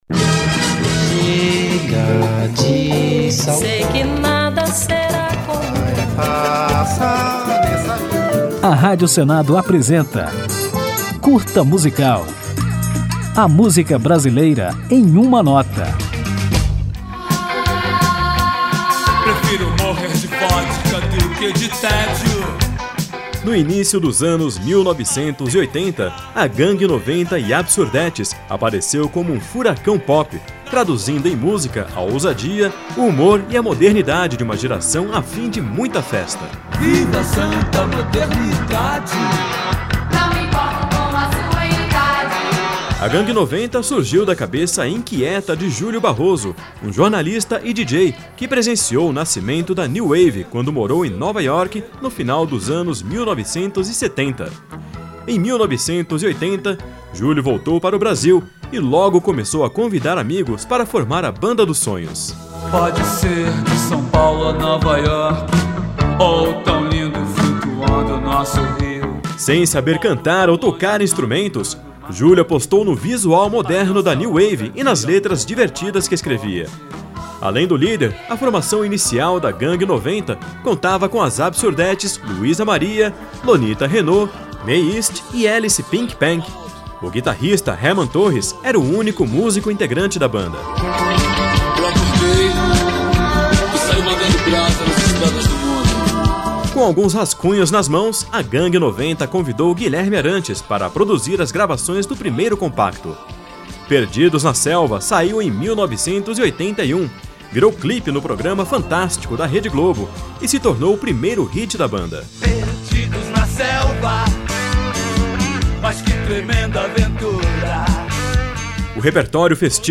Nesse Curta Musical vamos conhecer um pouco da história da Gang 90 e As Absurdettes e ainda ouvir a banda no sucesso Nosso Louco Amor.